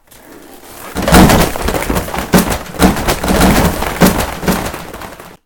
本棚倒れる
books_fall2.mp3